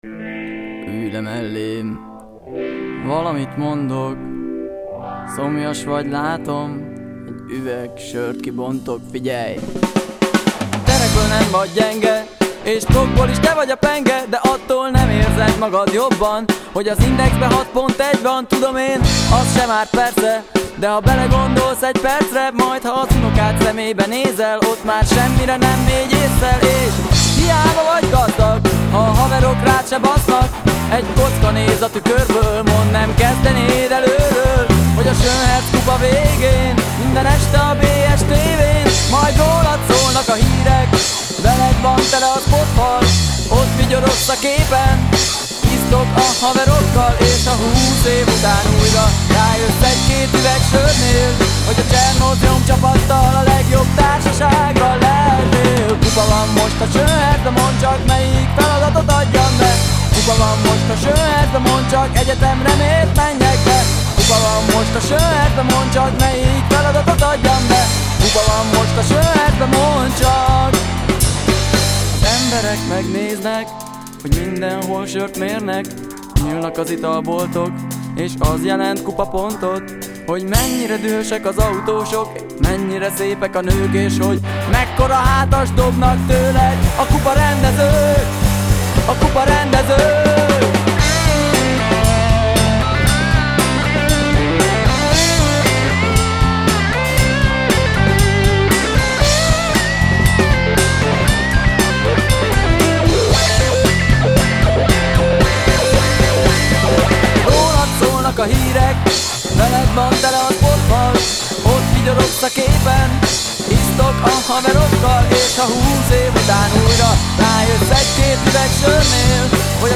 átdolgozás